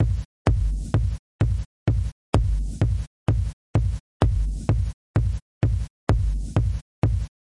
基本4/4节拍120bpm " 节拍120bpm04
描述：基本4/4击败120bpm
Tag: 回路 节奏 drumloop 120BPM 节拍 量化 有节奏 常规